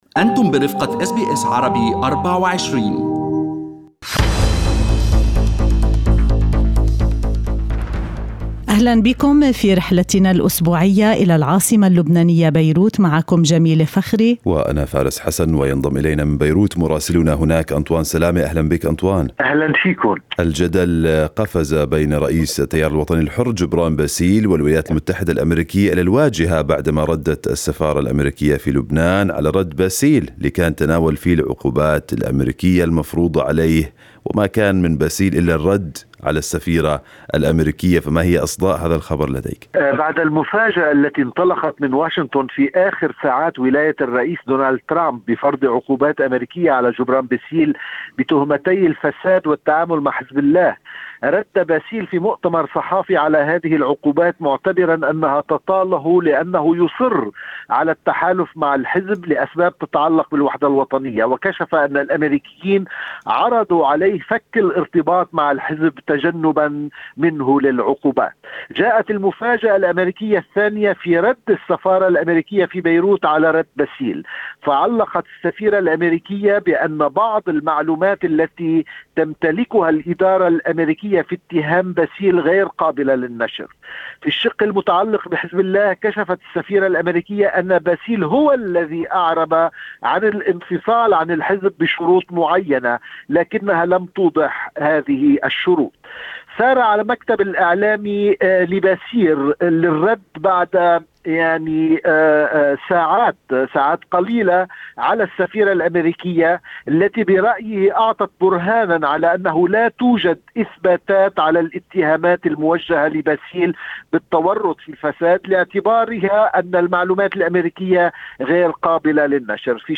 يمكنكم الاستماع إلى تقرير مراسلنا في بيروت بالضغط على التسجيل الصوتي أعلاه.